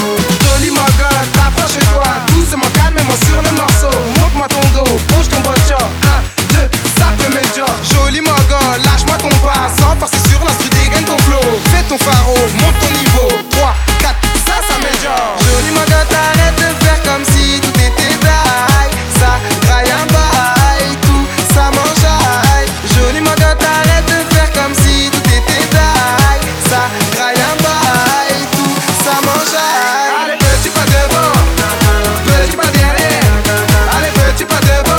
Жанр: Поп музыка / Танцевальные